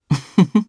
Fluss-Vox_Happy2_jp.wav